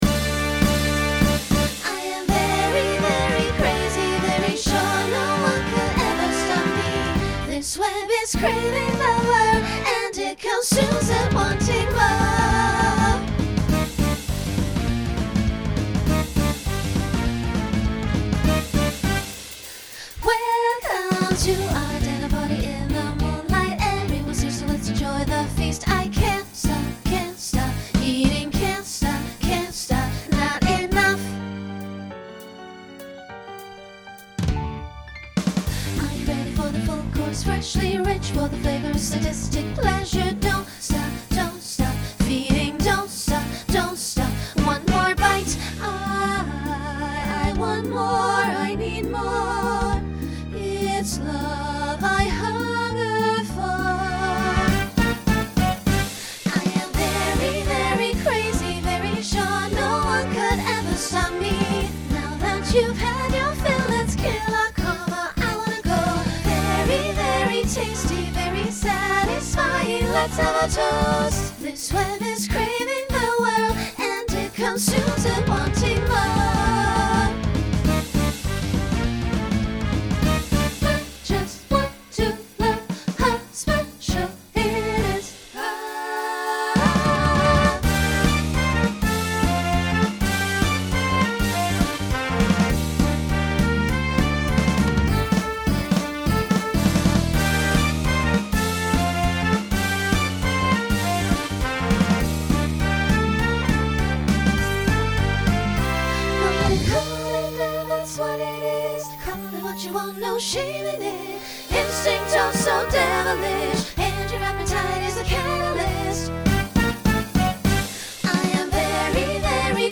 Genre Rock , Swing/Jazz Instrumental combo
Voicing SSA